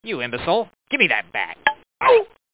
Amiga 8-bit Sampled Voice
imbecile.mp3